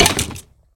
Minecraft Version Minecraft Version 25w18a Latest Release | Latest Snapshot 25w18a / assets / minecraft / sounds / mob / skeleton / hurt1.ogg Compare With Compare With Latest Release | Latest Snapshot
hurt1.ogg